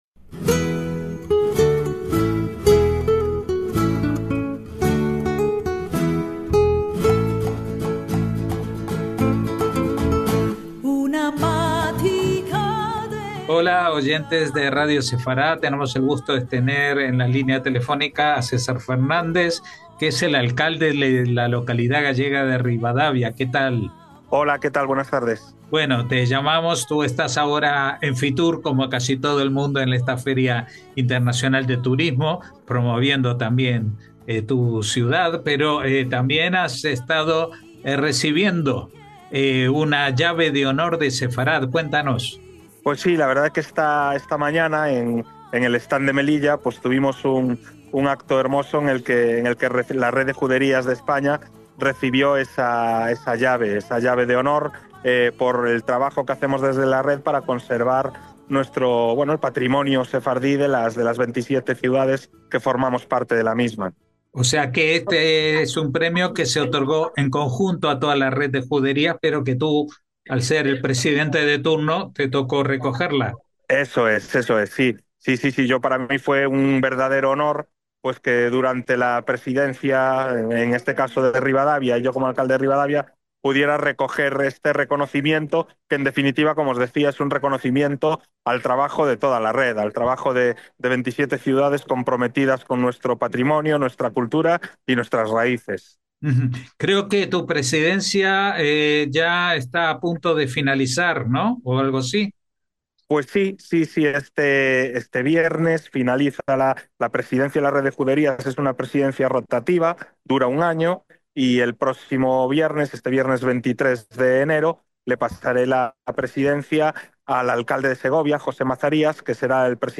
Porque, como dice nuestro entrevistado, no se puede entender el presente sin conocer el pasado. Hablamos también de la huella de aquella presencia judía en la localidad gallega sonde hoy, además, se encuentra el Museo Sefardí de Galicia.